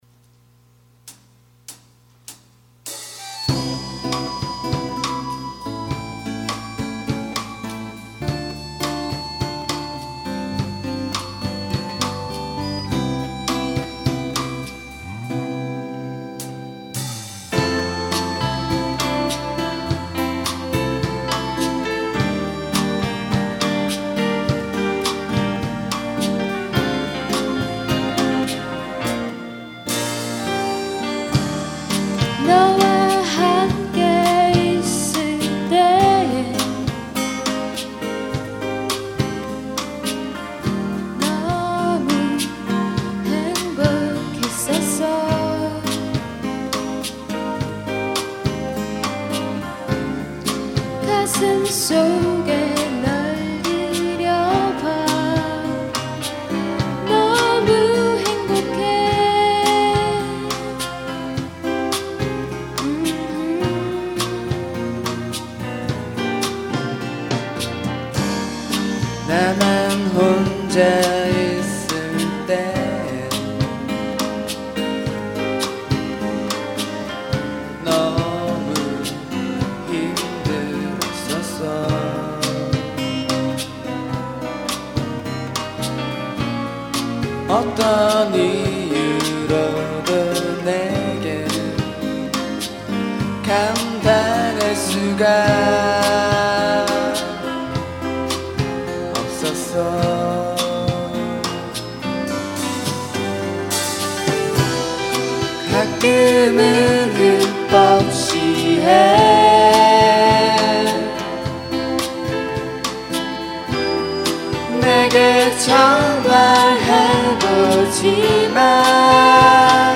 2005년 신입생 환영공연
홍익대학교 신축강당
어쿠스틱 기타
드럼
퍼커션